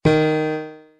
FueraDeEscala/Assets/Game Kit Controller/Sounds/Piano Keys C Scale New/d1s.wav at 1e752fce75d7447cdbee44b8826d64d13bf99b3a